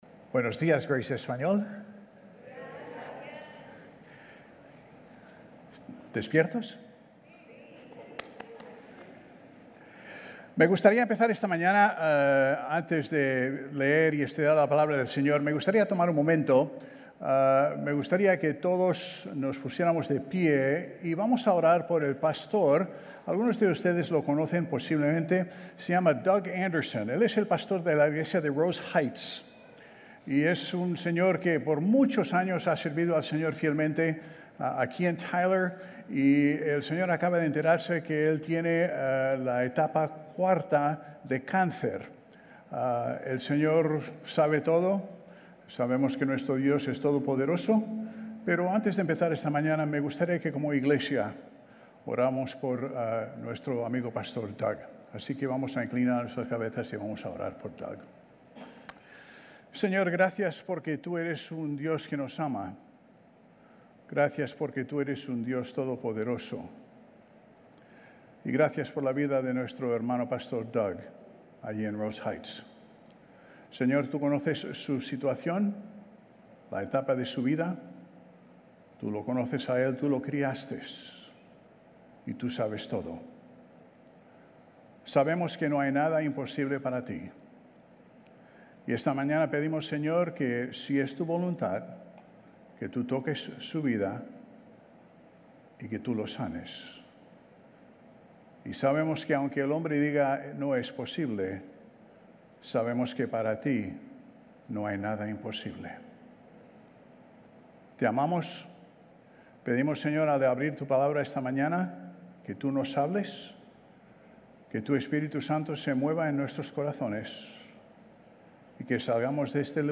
GCC-GE-August-28-Sermon.mp3